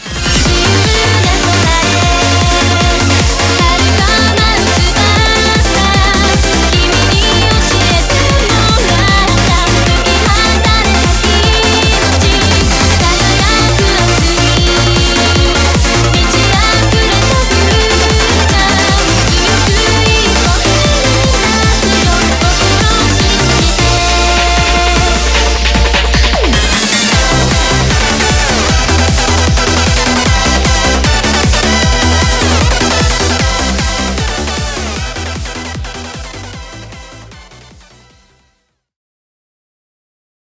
EUROBEAT